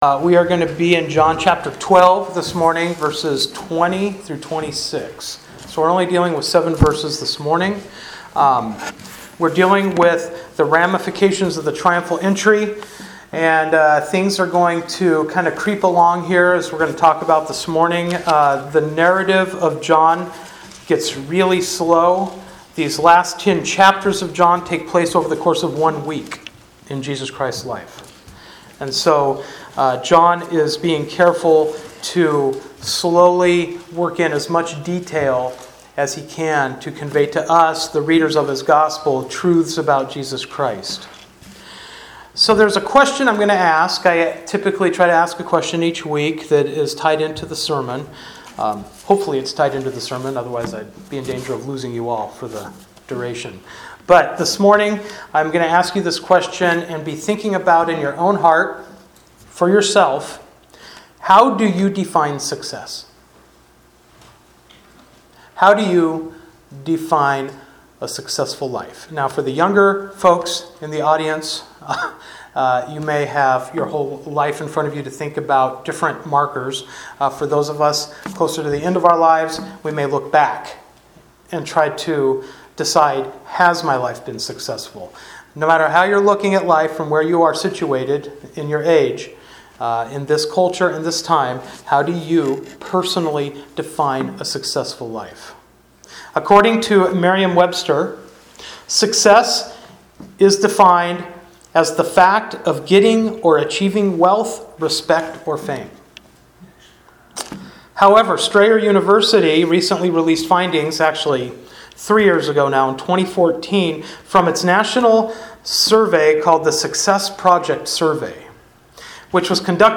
John 12:20-26 Service Type: Sunday Morning Worship Bible Text